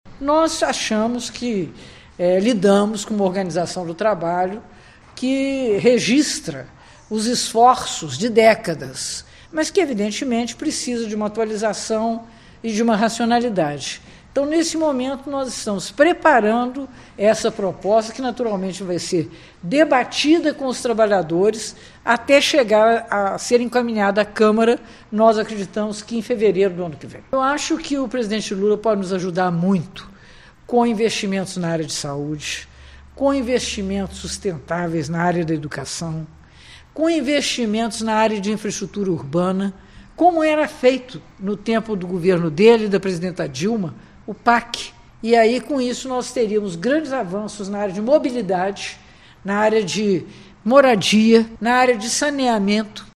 A prefeita de Juiz de Fora, Margarida Salomão (PT) concedeu entrevista à imprensa para falar sobre o orçamento do executivo municipal e dar um panorama da gestão.
02-Entrevista-Margarida-3.mp3